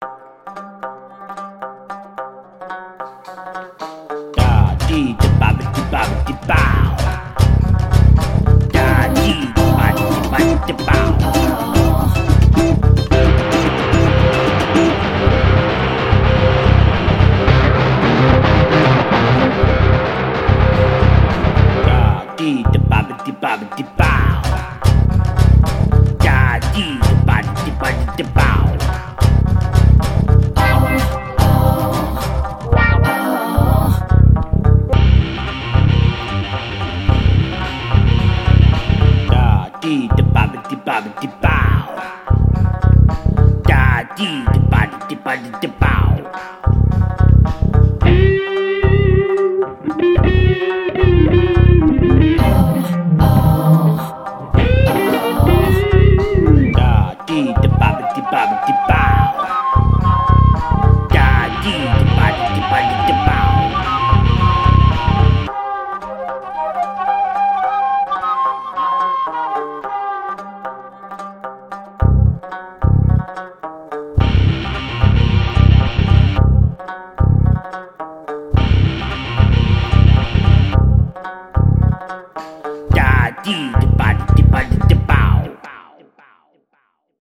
I just downloaded GarageBand on the iPad and so this is me having a play.